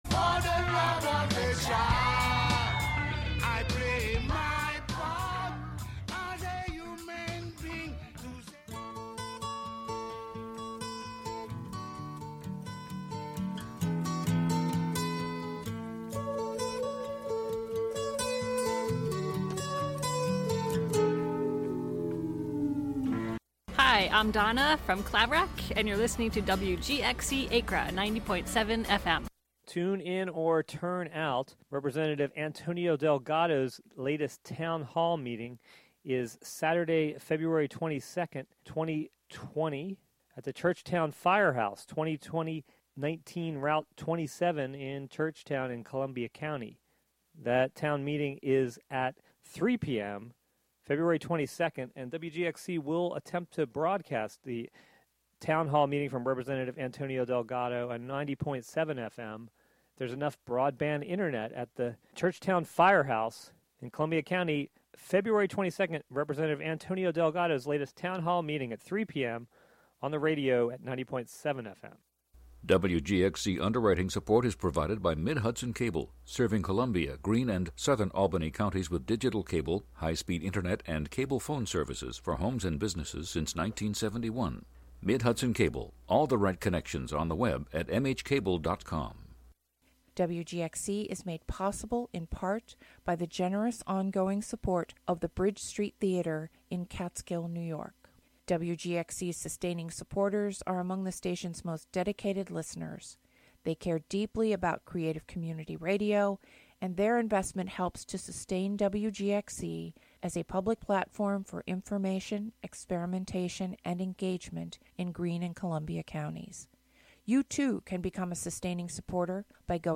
On this show you will hear songs and sounds from a variety of genres as well as from unclassifiable styles of music and experimentation. The show will sometimes feature live performances from near and far and periodically unpredictable guests will join to share music.